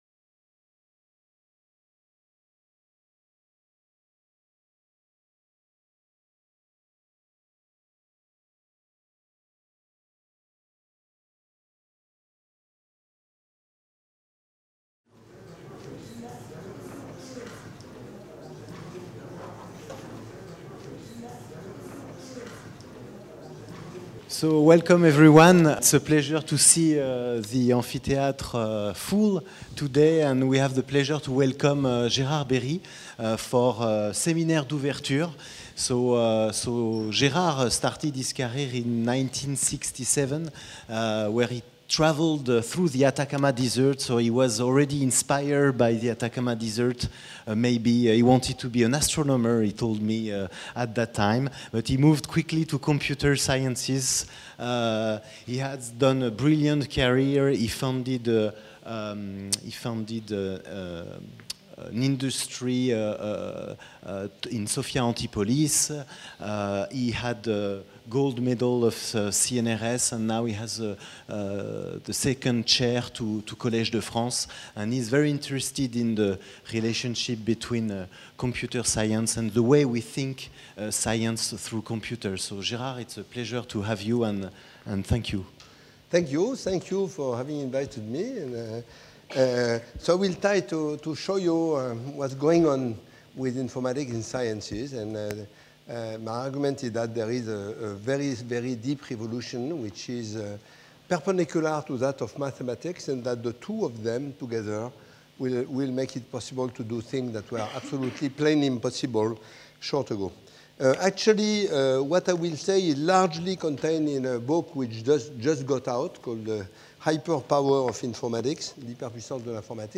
Séminaire spécialisé (en anglais), par Gérard Berry, titulaire de la Chaire algorithmes, machines et langages, Mathématiques et Sciences Numériques du Collège de France, médaille d'or 2014 du CNRS. In natural sciences and mathematics, informatics has long been only seen as an auxiliary tool necessary to perform the heavy computations needed to numerically solve mathematical equations that could not be solved analytically.